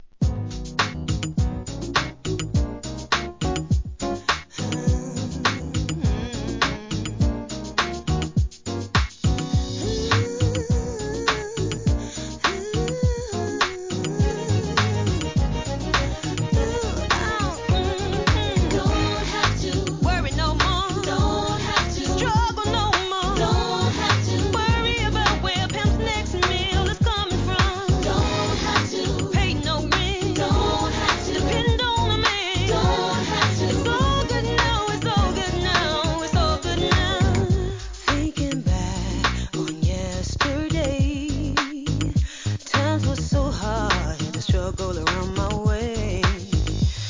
HIP HOP/R&B
2001年、GROOVYなフィメール・デュオ作品!